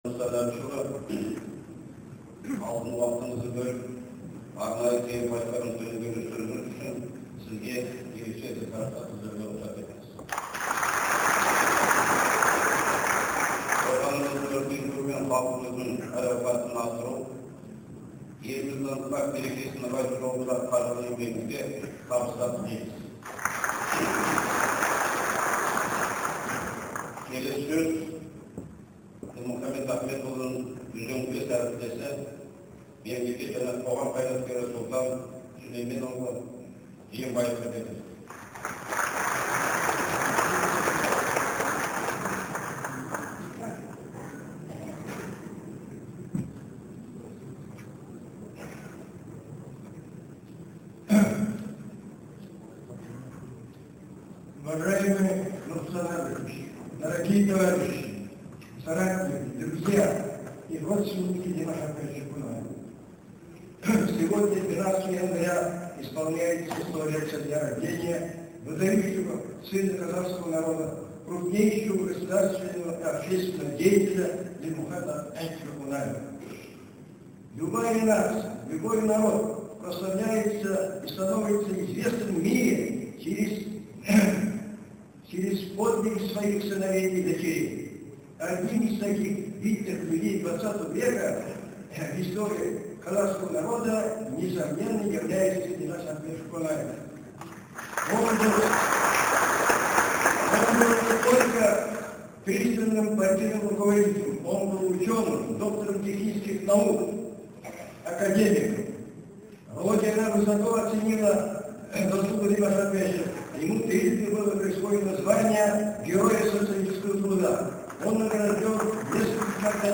Бибігүл Төлегенованың сөзі